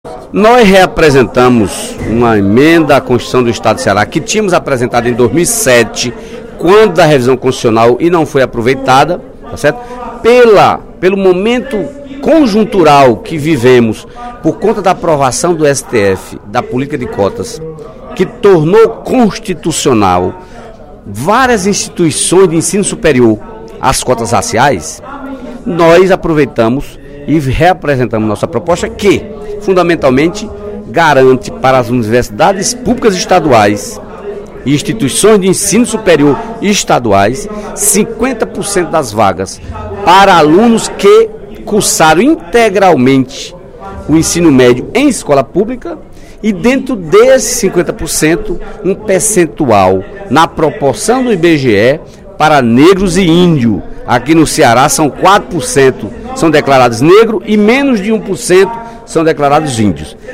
O deputado Dedé Teixeira (PT) defendeu, na tribuna da Assembleia Legislativa, na manhã desta quinta-feira (24/05), o projeto de emenda à Constituição Estadual de sua autoria, nº 02/2012, que estabelece reserva de vagas nas instituições estaduais de educação superior (IES) para alunos da escola pública, negros e índios.